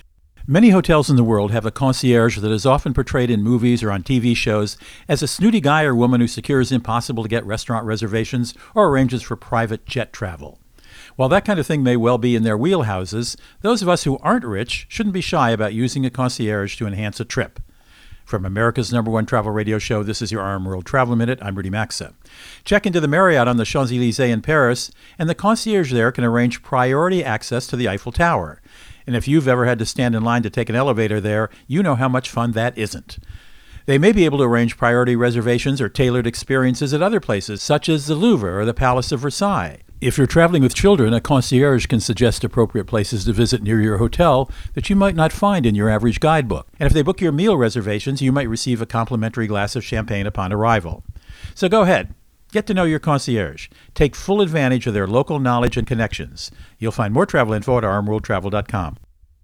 America's #1 Travel Radio Show
Co-Host Rudy Maxa | Praise for the Concierge